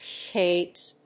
/kevz(米国英語), keɪvz(英国英語)/